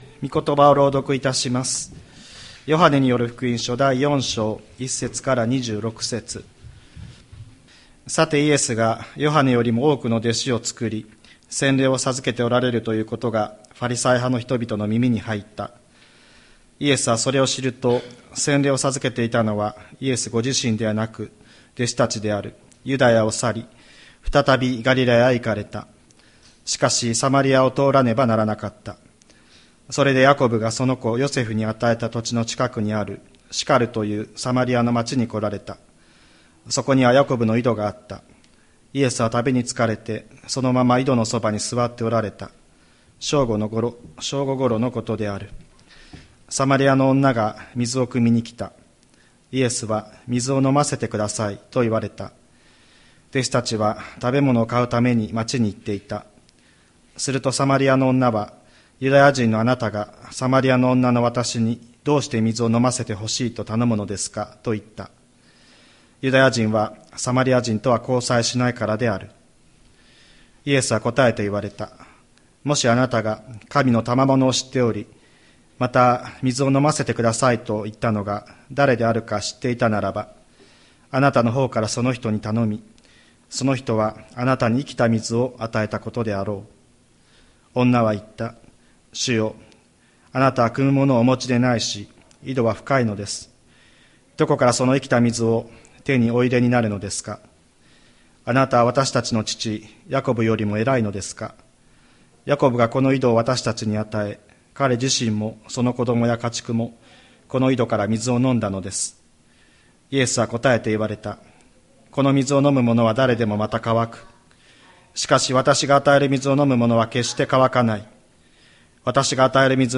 2023年08月13日朝の礼拝「渇いた者にいのちの水を」吹田市千里山のキリスト教会
千里山教会 2023年08月13日の礼拝メッセージ。